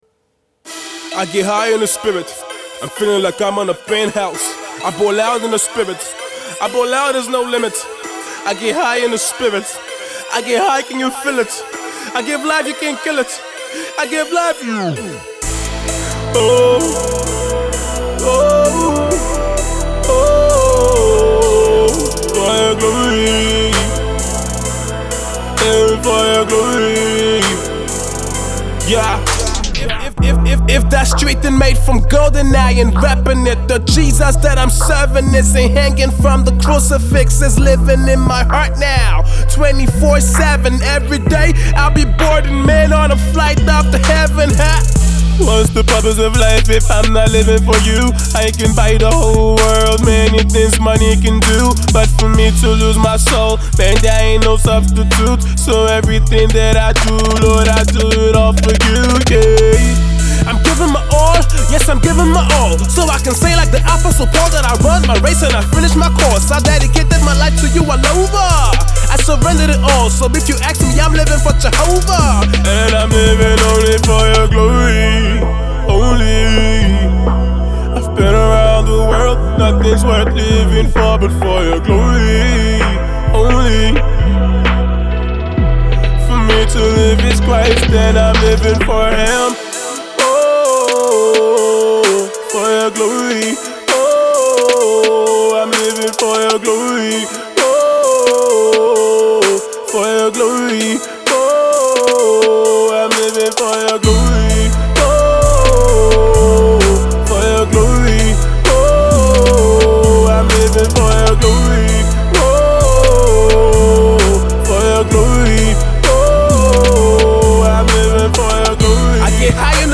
urban gospel